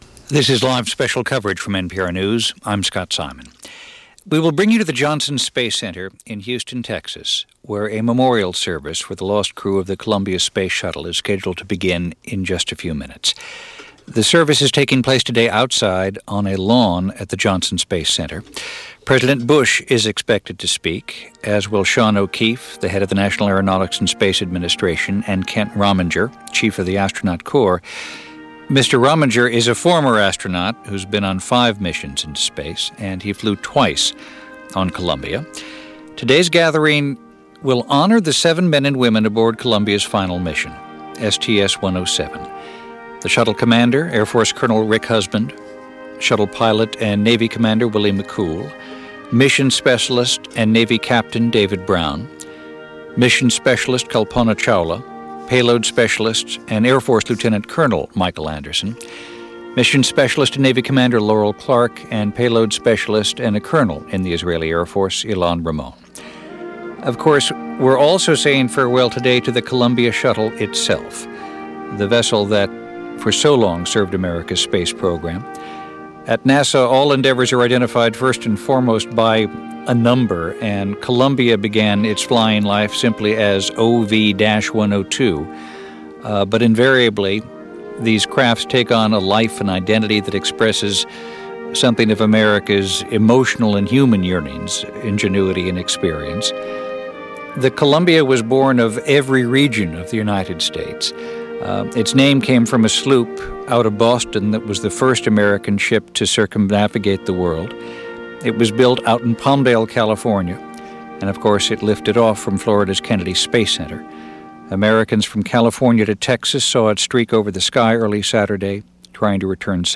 February 4, 2003 - Memorial For Shuttle Columbia - Broadcast live by National Public Radio from Houston Space Center - Past Daily Reference Room.